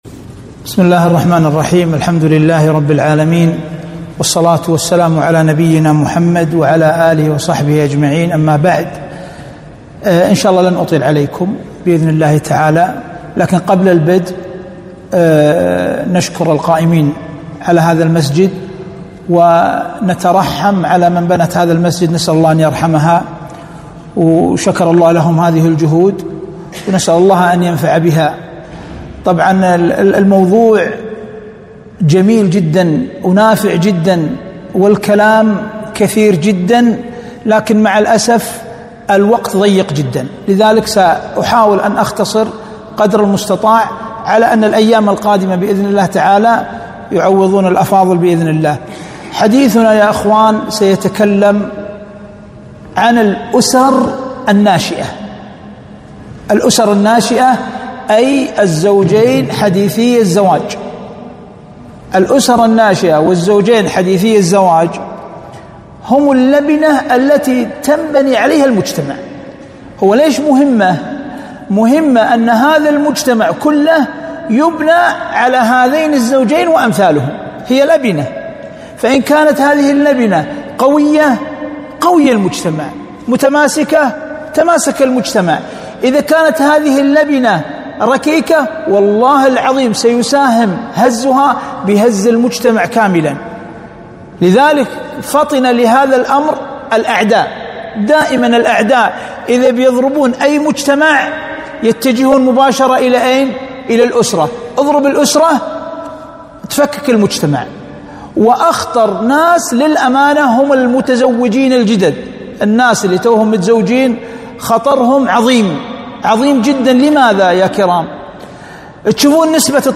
محاضرة - إضاءات في حياة الأسرة الناشئة